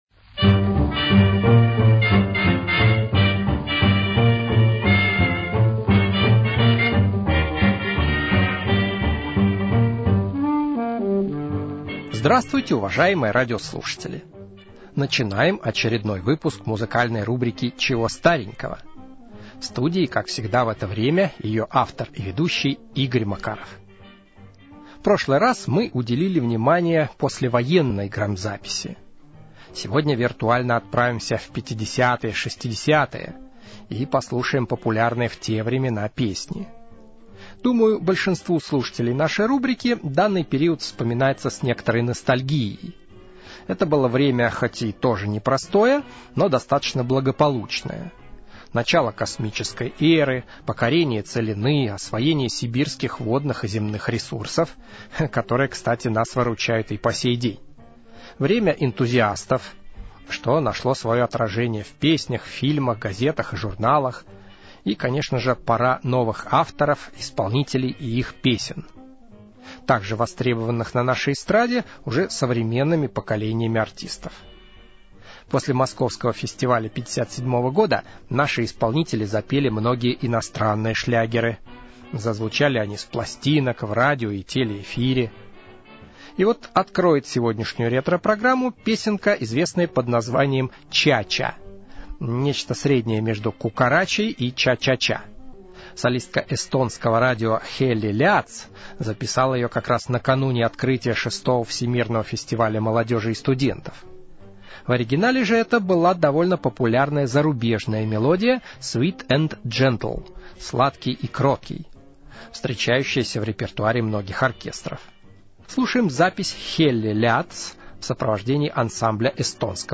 CCXVII - Записи 50-60-х годов (Ча-ча)